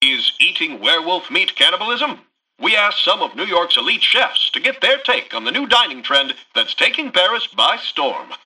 Newscaster_headline_32.mp3